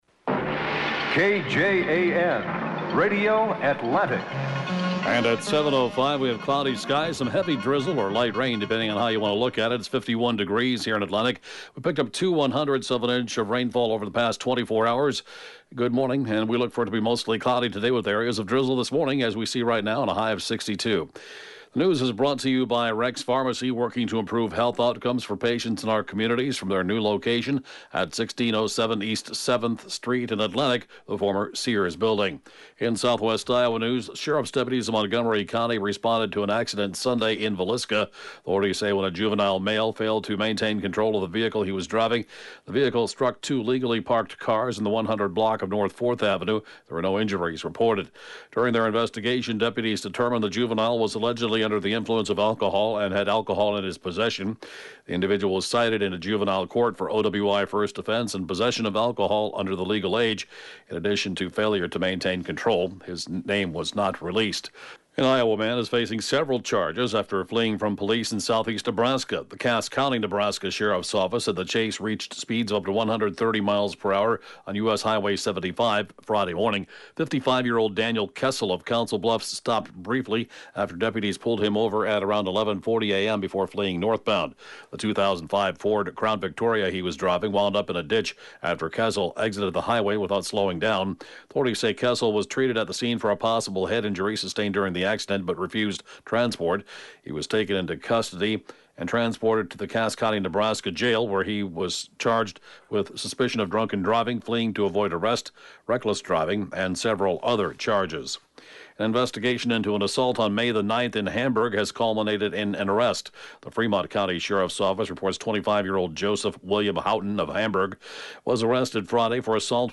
(Podcast) KJAN Morning News & Funeral report, 5/18/20